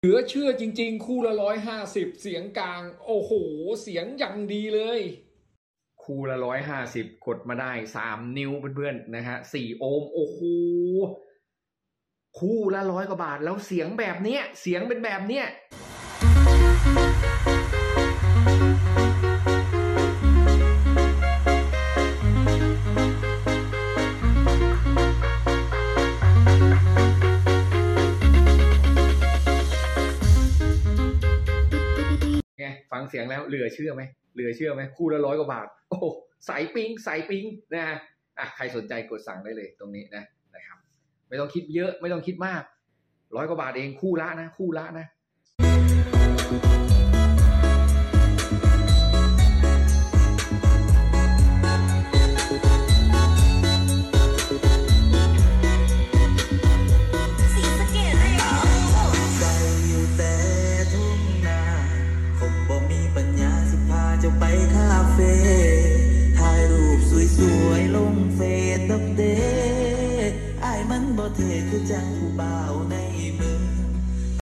เสียงกลาง 3 นิ้วคู่ละ 100 กว่าบาทเหลือจะเชื่อเสียงอย่างดีแพลตตินั่มเอ็กซ์